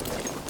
Gear Rustle Redone
tac_gear_8.ogg